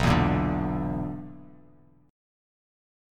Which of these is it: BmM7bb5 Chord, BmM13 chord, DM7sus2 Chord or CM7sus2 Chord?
BmM7bb5 Chord